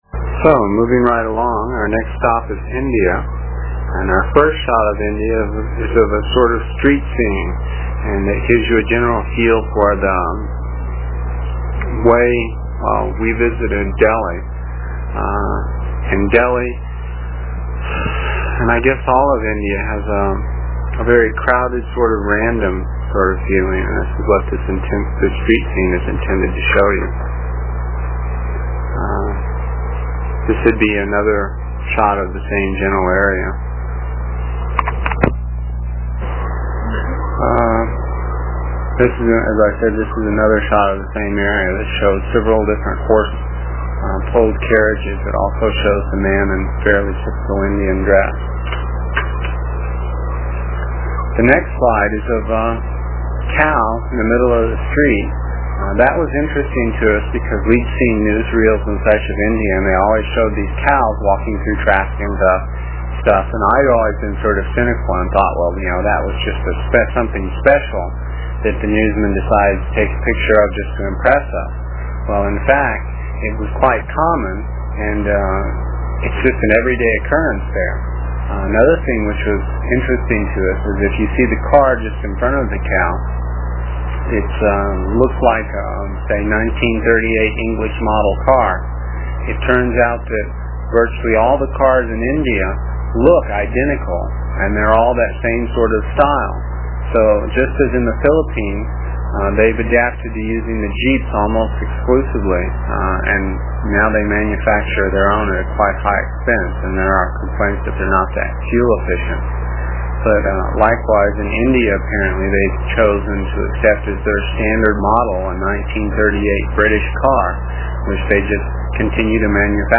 It is from the cassette tapes we made almost thirty years ago. I was pretty long winded (no rehearsals or editting and tapes were cheap) and the section for this page is about eight minutes and will take about three minutes to download with a dial up connection.